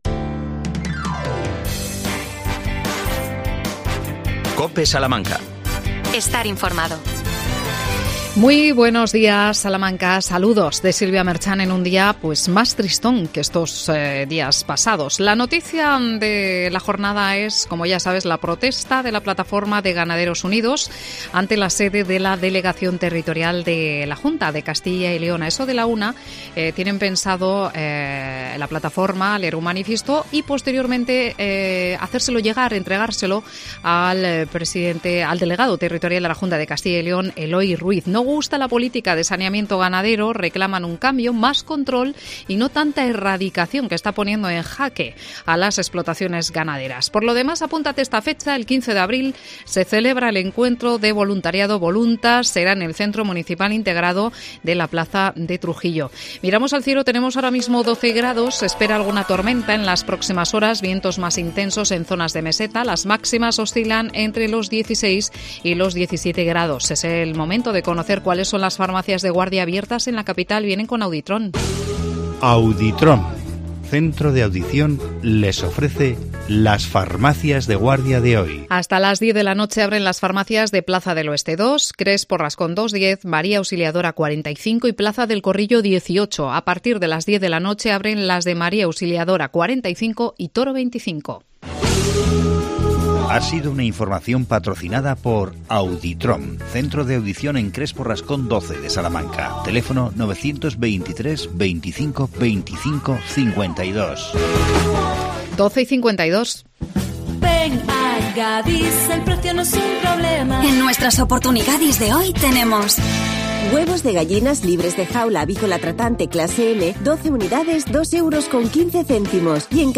AUDIO: Entrevista al concejal de Juventud Angel Fernández Silva. El tema: el Lunes de Aguas.